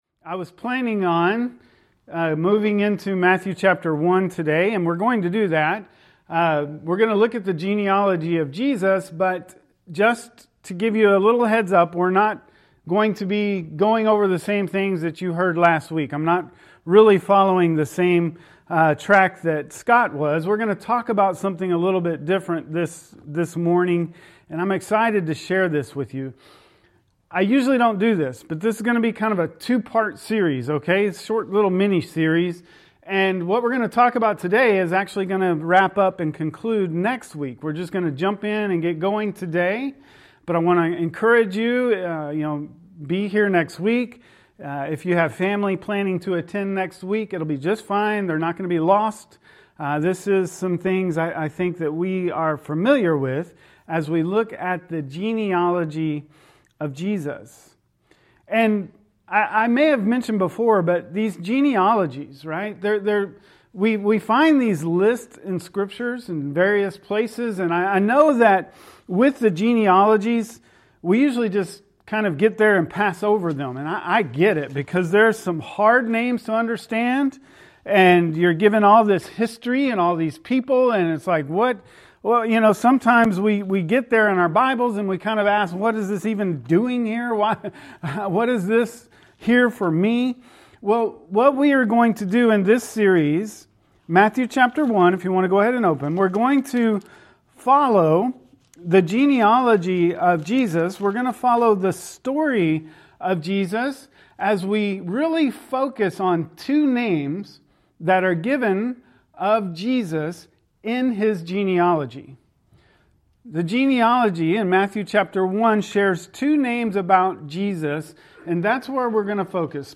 A GENESIS STORY (pt. 1) lesson audio This season is when many consider the birth of Jesus and the story of Jesus.